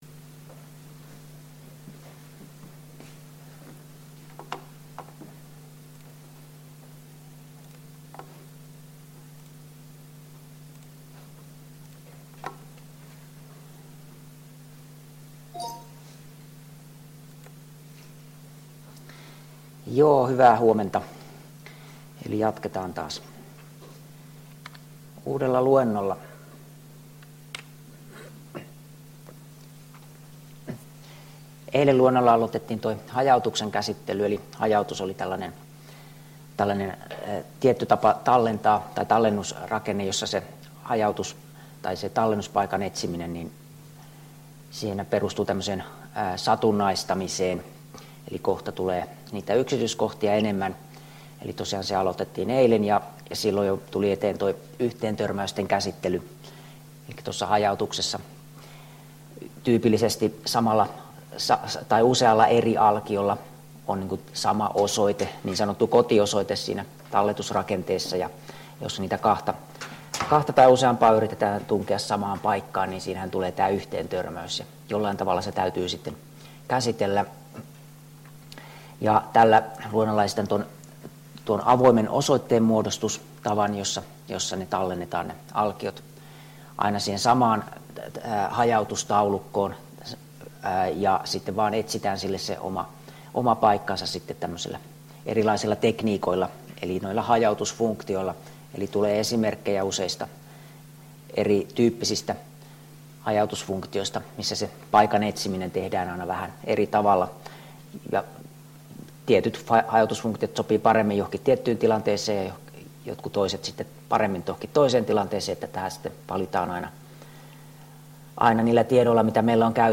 Luento 4 — Moniviestin